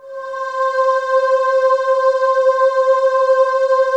Index of /90_sSampleCDs/USB Soundscan vol.28 - Choir Acoustic & Synth [AKAI] 1CD/Partition D/11-VOICING